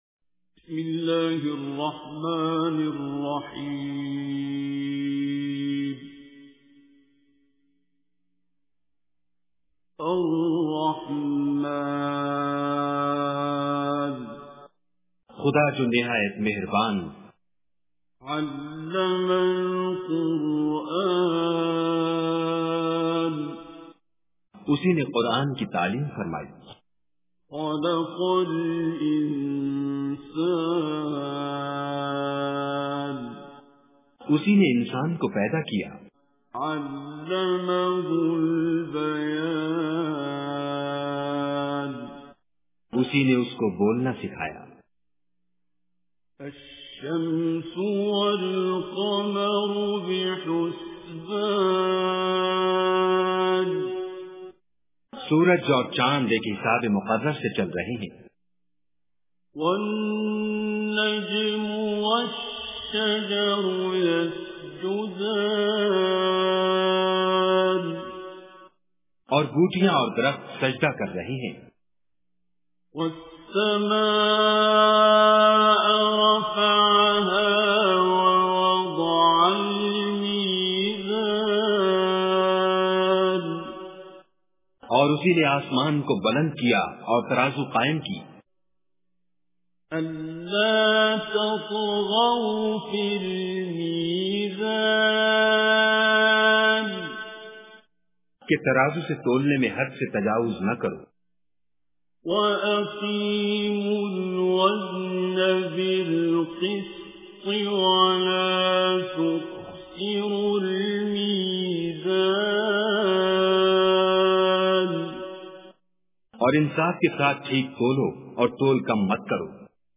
Surah Rahman Recitation with Urdu Translation
Surah Rahman is 55th Surah of Holy Quran. Listen online and download mp3 tilawat / recitation of Surah Rehman in the voice of Qari Abdul Basit As Samad.